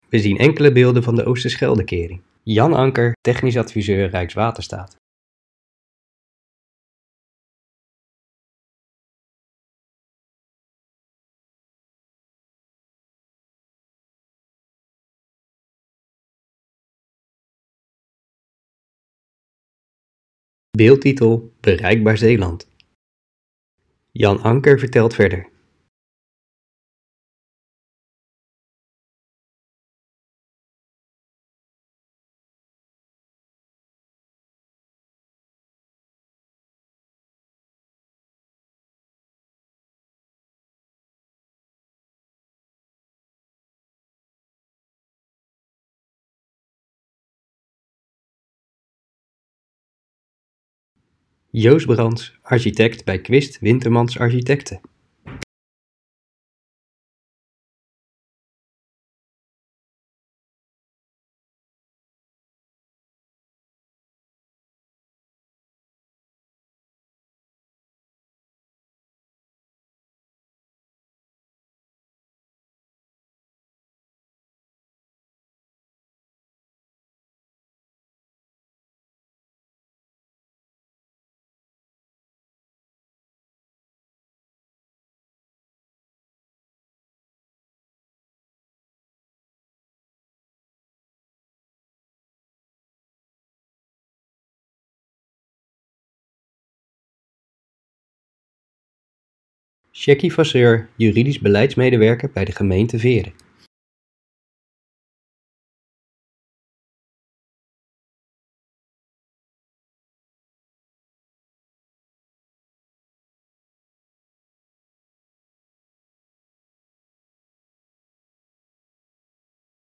In deze video leggen Rijkswaterstaat, Quist Wintermans Architekten en de gemeente Veere uit wat daarbij kwam kijken.